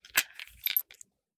38 SPL Revolver - Unloading 001.wav